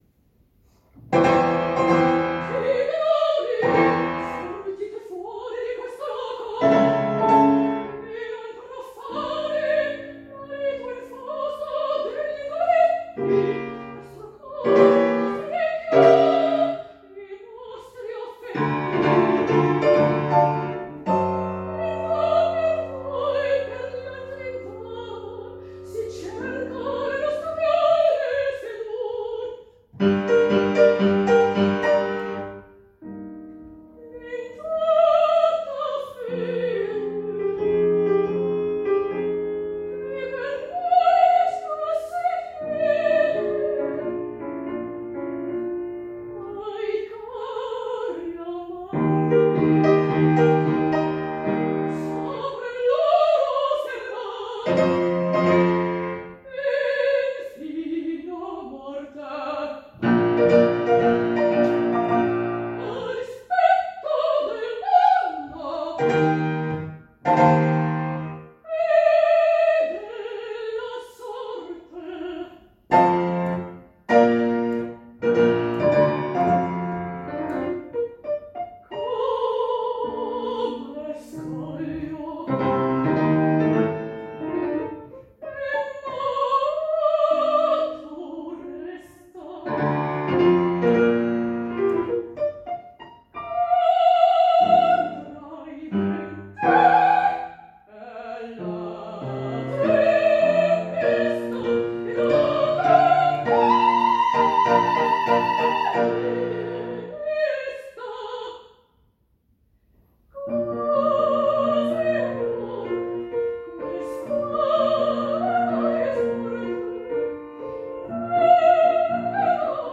Oper/Lied